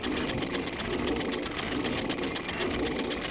Golf_Windmill_Loop.ogg